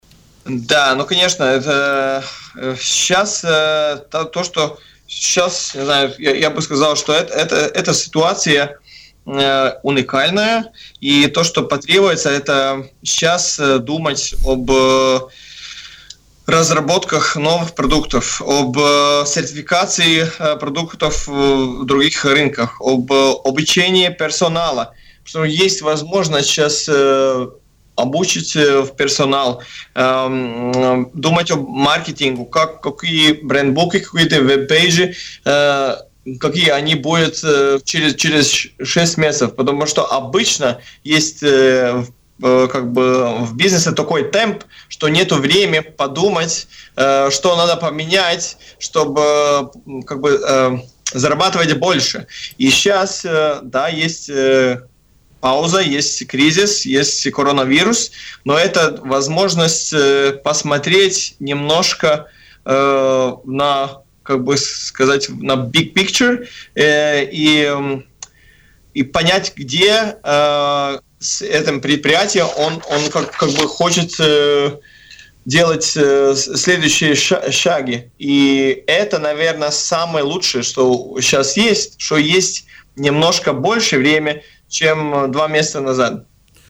Такое мнение в эфире радио Baltkom высказал директор Латвийского агентства инвестиций и развития Каспарс Рожкалнс.